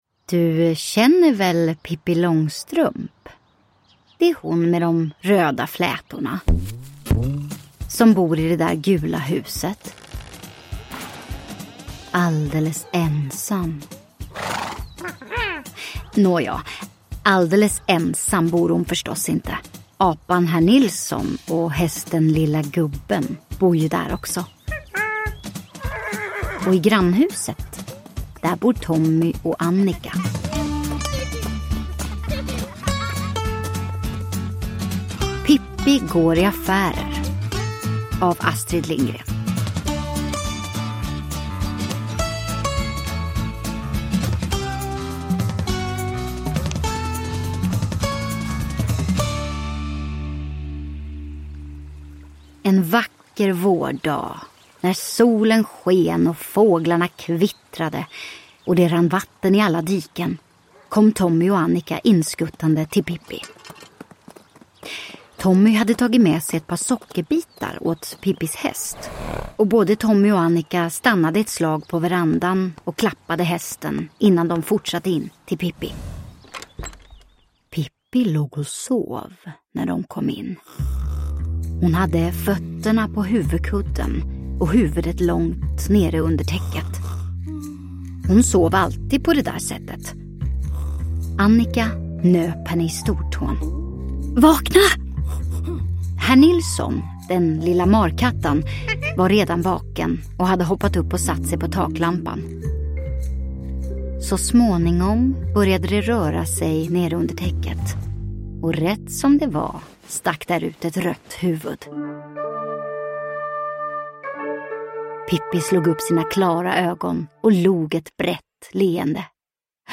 Pippi går i affärer (Ljudsaga) – Ljudbok
Ny inläsning av Pippi Långstrump med rolig ljudläggning!